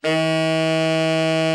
TENOR 9.wav